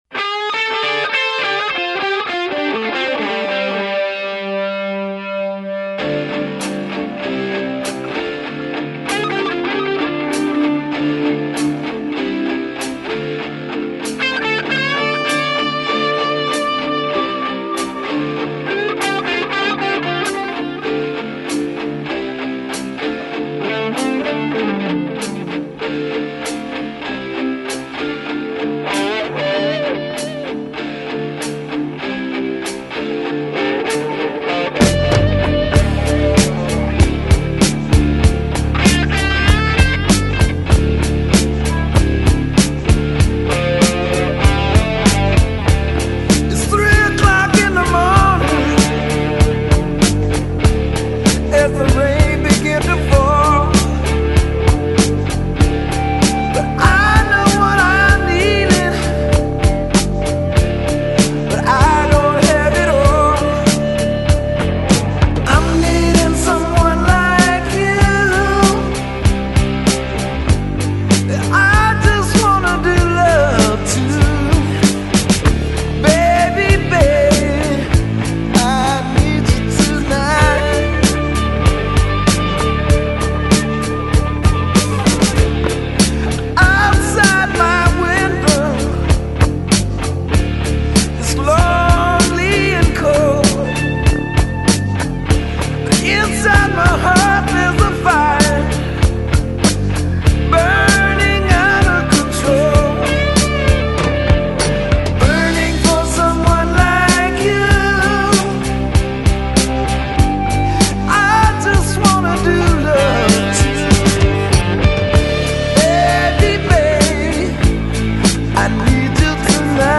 Hard Rock, Blues Rock, Synth-Pop